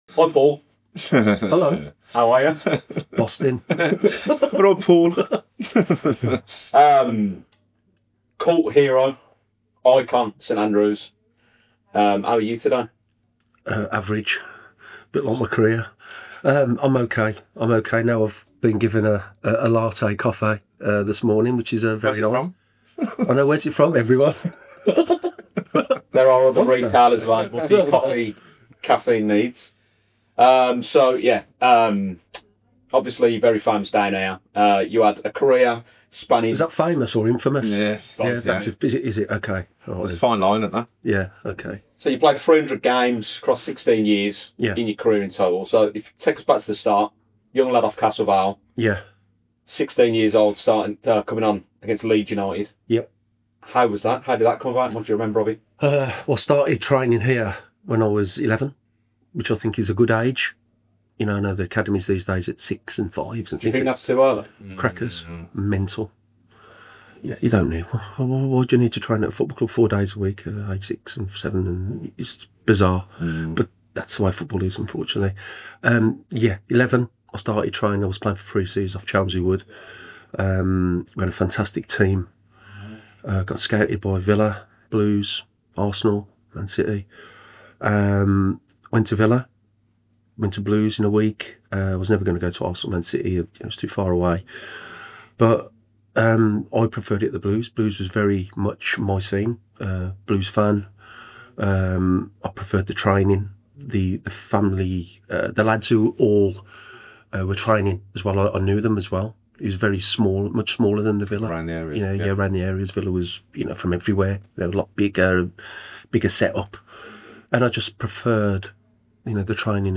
Paul Tait is a name written into Birmingham City folklore — from that infamous Villa T-shirt moment to years of grit, goals, and graft in the famous royal blue. But behind the headlines was a story of battles off the pitch. In this candid conversation, Taity opens up about: • ...